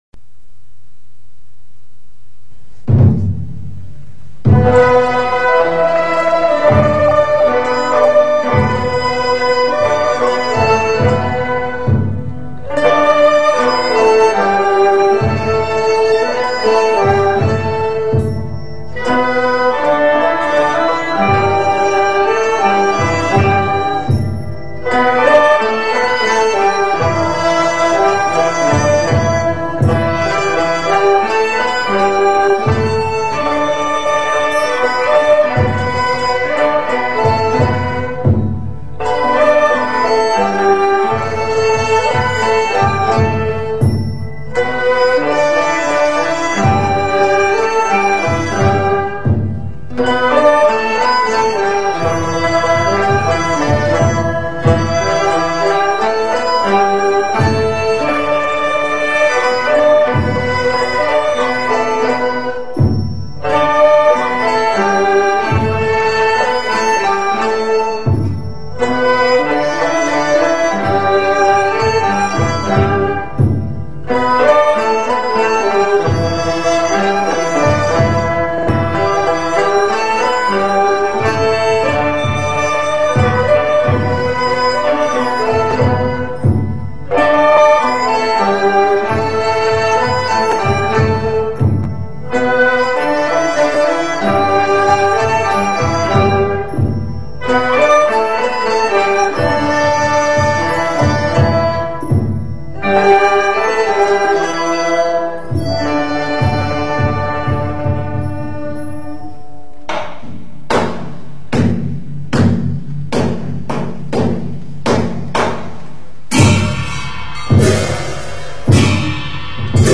中国道教音乐 浙江韵 步虚韵
此首曲子，为河北道协在鹿泉十方院录制，韵调为浙江韵。《步虚韵》为全真道教晚课第一首唱诵曲子，